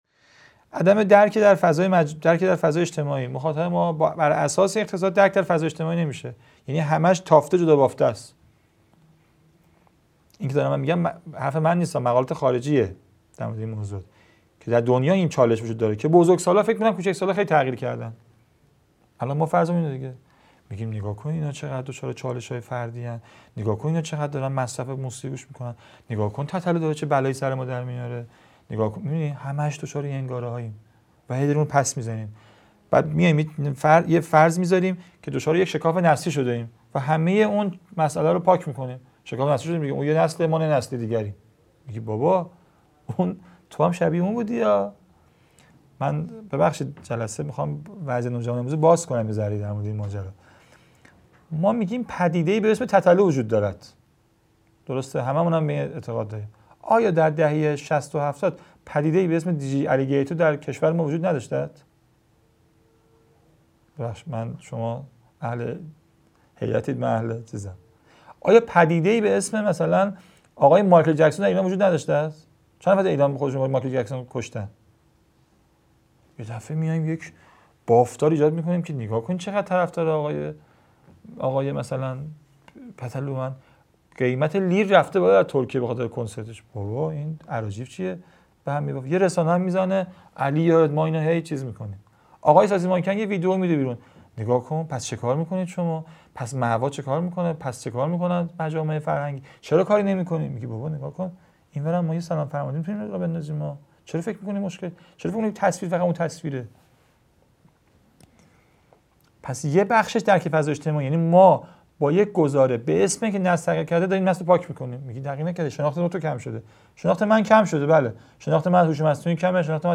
مدرسه عالی هیأت | گزیده یازدهم از دومین سلسله نشست‌ های هیأت و نوجوانان - با موضوعیت نوجوان در ایران
قم - اردبیهشت ماه 1402